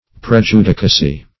Search Result for " prejudicacy" : The Collaborative International Dictionary of English v.0.48: Prejudicacy \Pre*ju"di*ca*cy\, n. Prejudice; prepossession.